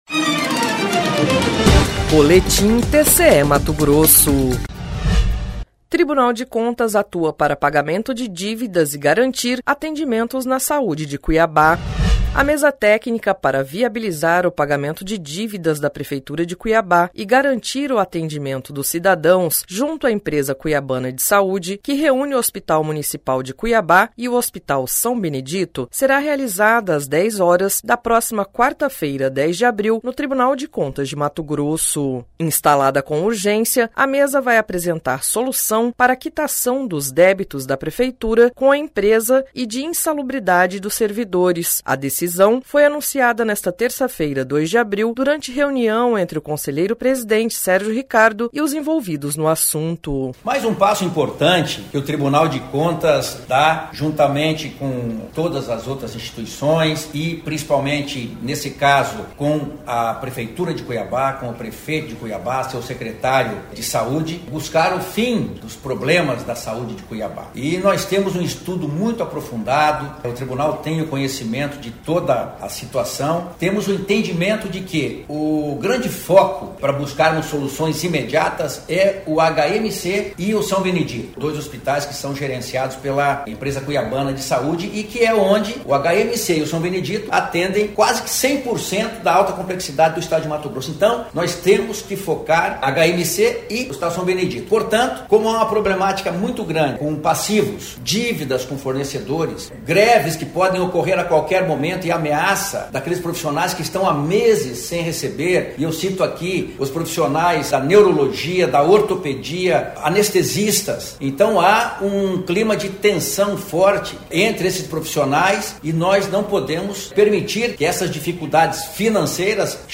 Sonora: Sérgio Ricardo – conselheiro-presidente do TCE-MT
Sonora: José Carlos Novelli – conselheiro do TCE-MT
Sonora: Guilherme Antonio Maluf – conselheiro do TCE-MT
Sonora: Emanuel Pinheiro – prefeito de Cuiabá